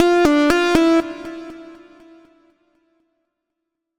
フリー効果音：システム29
ゲーム効果音第29弾！ちょっぴりレトロで懐かしい感じのファミコン風SEです！